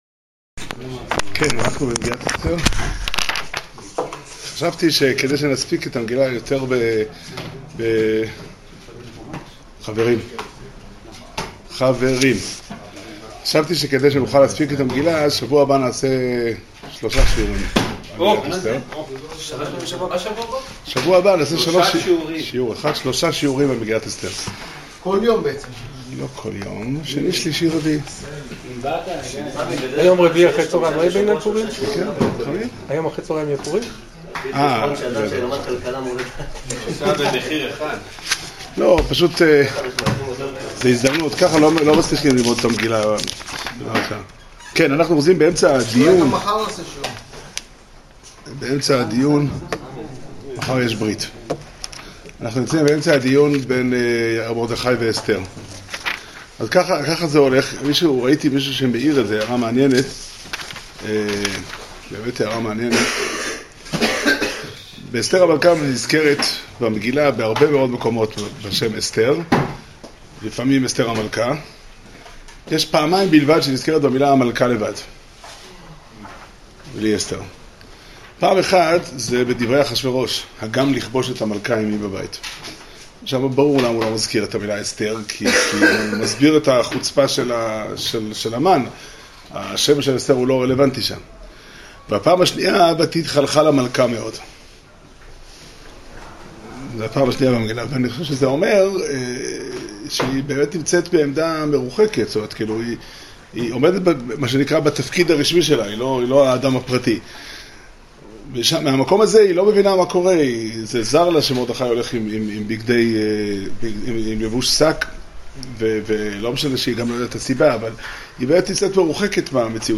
שיעור שנמסר בבית המדרש פתחי עולם בתאריך ו' אדר ב' תשע"ט